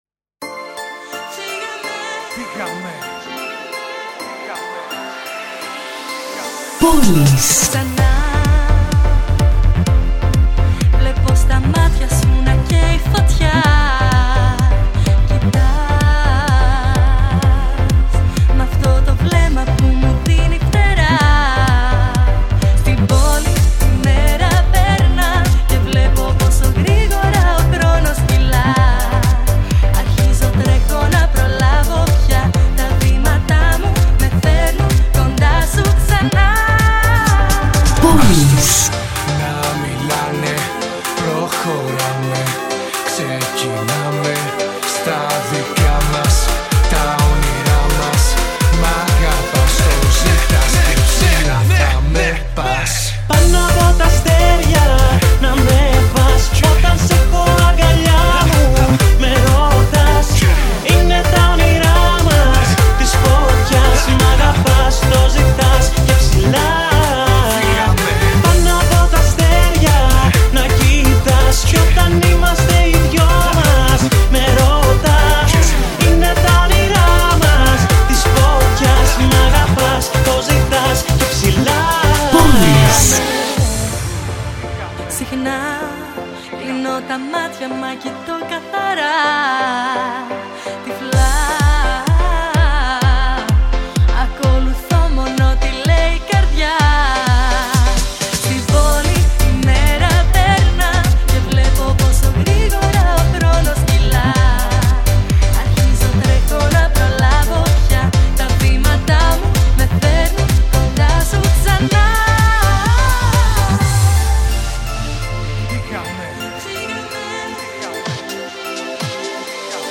το αποτέλεσμα είναι άκρως χορευτικό!